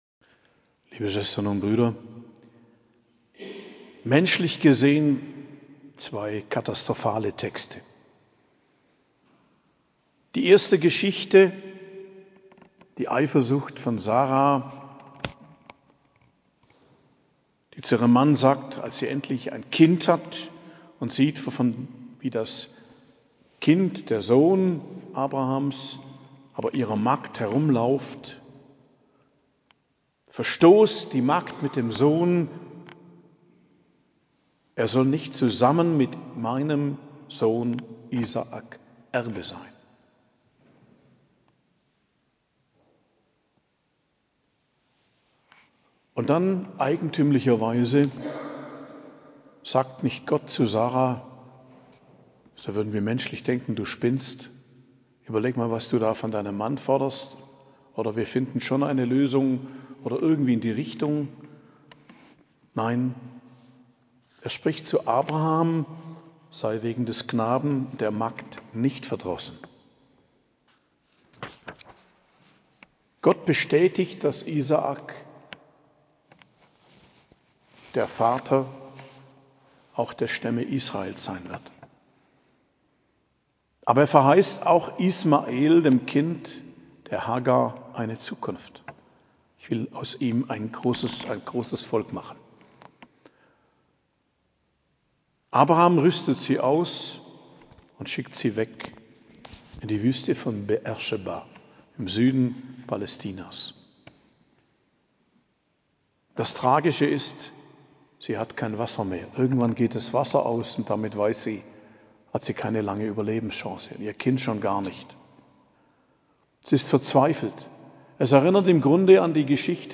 Predigt am Mittwoch der 13. Woche i.J., 5.07.2023